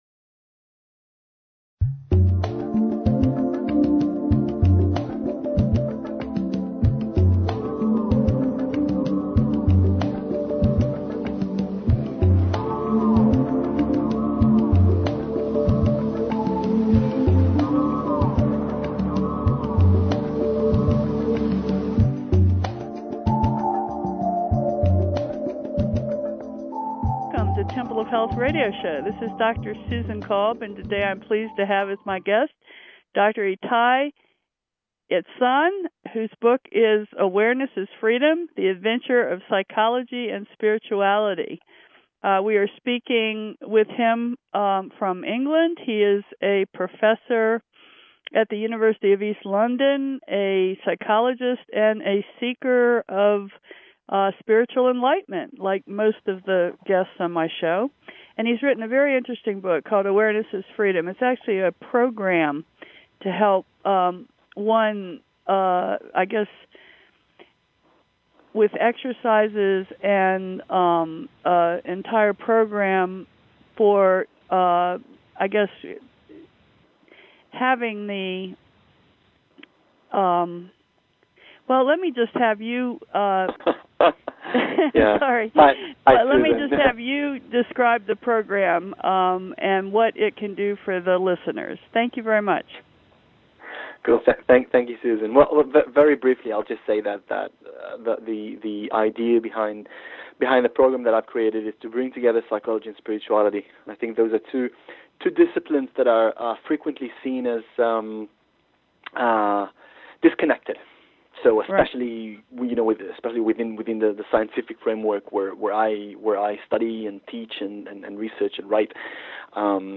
Temple of Health Radio Show